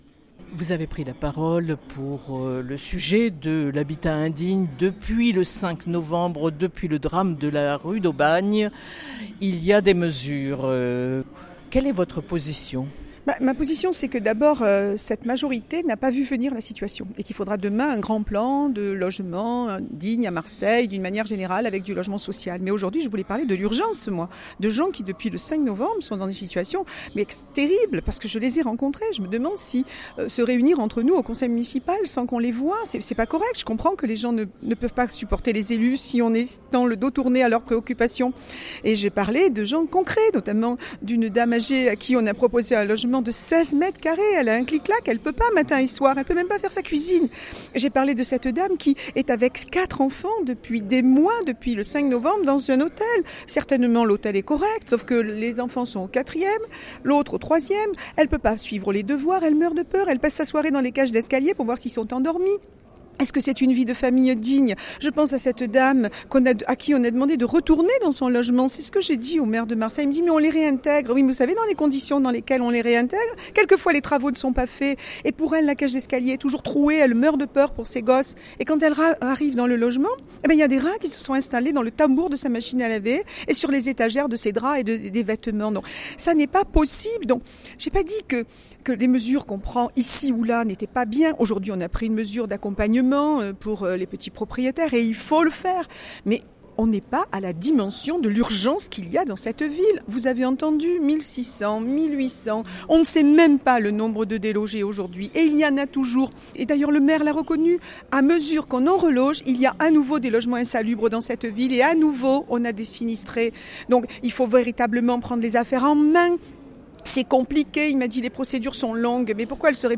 son_copie_petit-338.jpgMarie Arlette Carlotti, conseillère municipal socialiste intervient en séance pour dénoncer la négligence et «le manque de volontarisme» du maire de Marseille, Jean-Claude Gaudin parce qu’il y a «urgence».